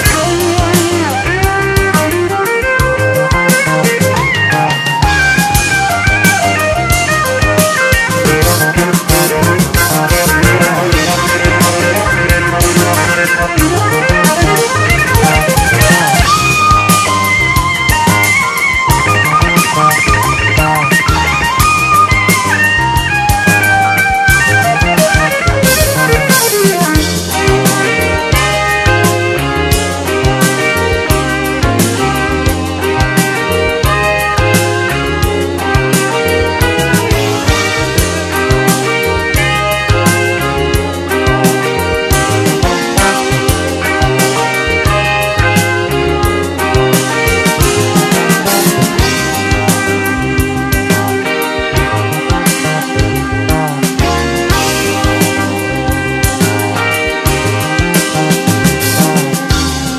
JAZZ / OTHER / JAZZ ROCK / 70'S / 70'S ROCK (US)